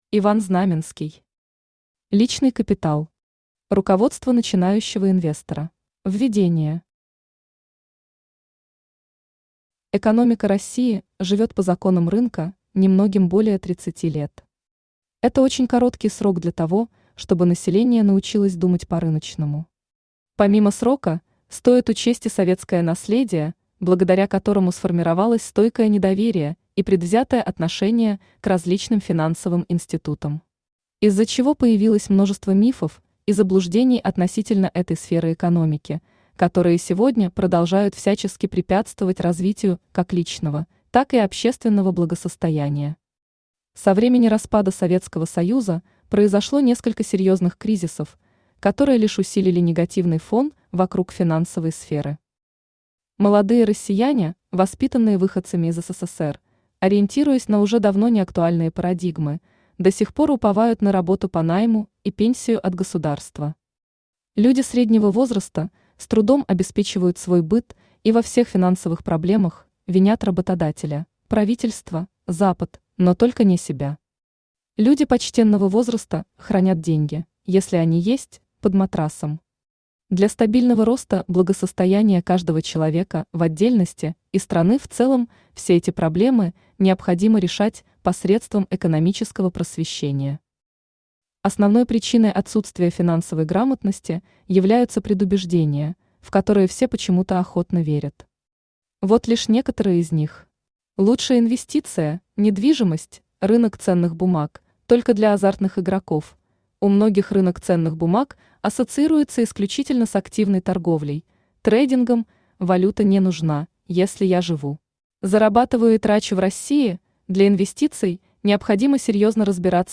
Аудиокнига Личный капитал. Руководство начинающего инвестора | Библиотека аудиокниг
Руководство начинающего инвестора Автор Иван Леонидович Знаменский Читает аудиокнигу Авточтец ЛитРес.